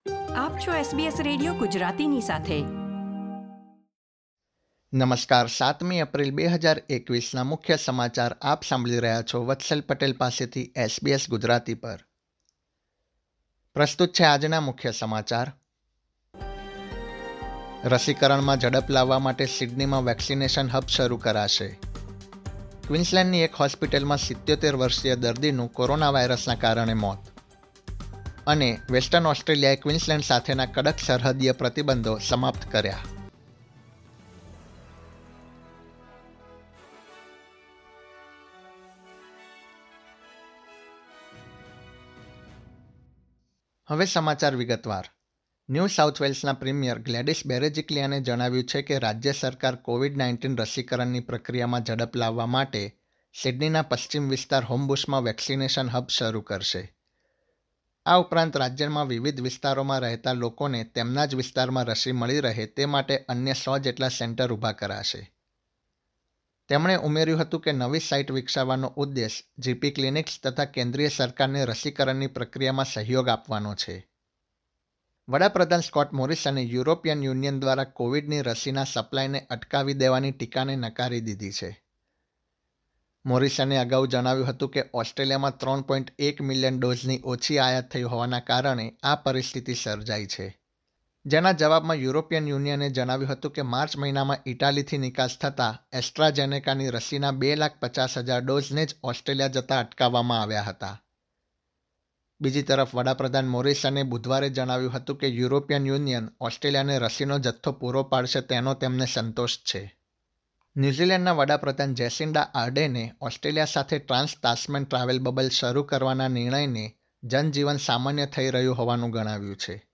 gujarati_0704_newsbulletin.mp3